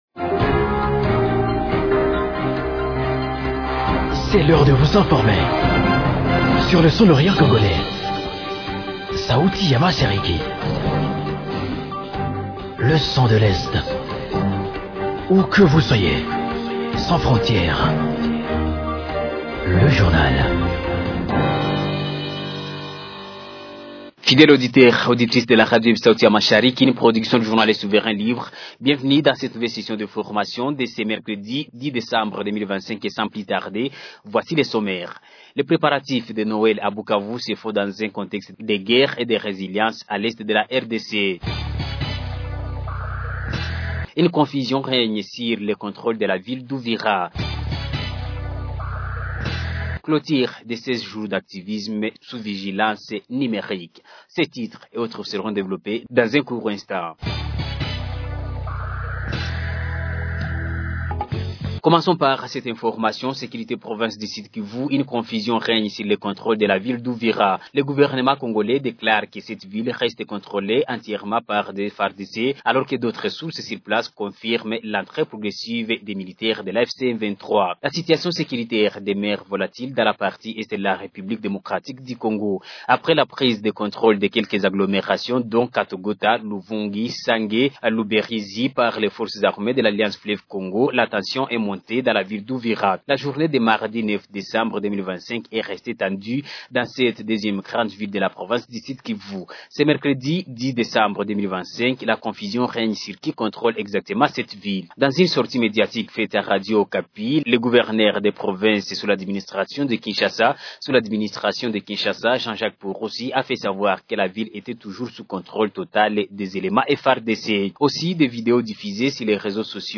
Journal du 10.12.2025